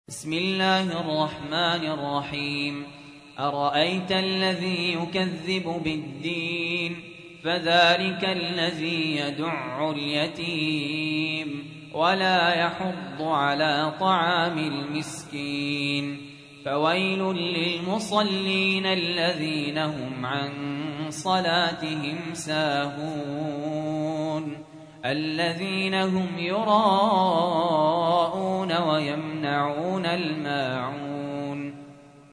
تحميل : 107. سورة الماعون / القارئ سهل ياسين / القرآن الكريم / موقع يا حسين